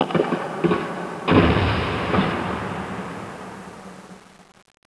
city_battle16.wav